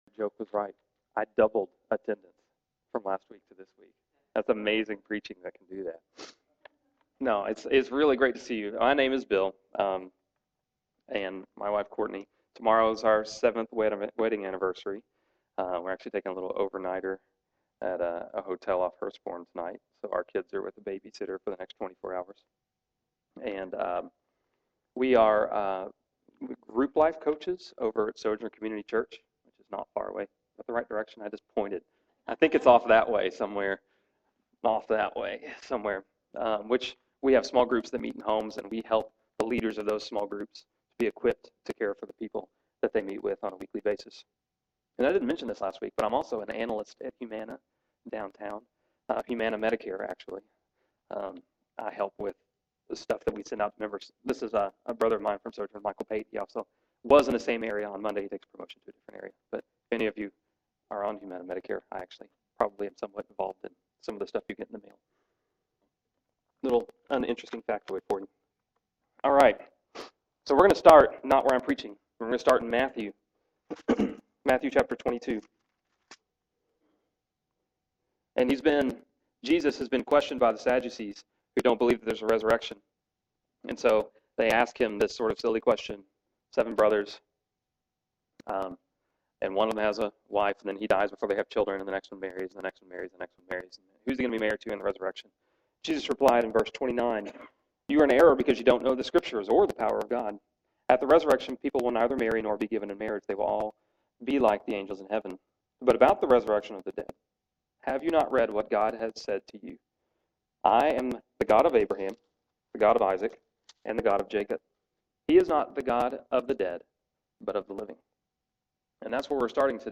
Here is the audio recording of the evening sermon for 05/23/2010.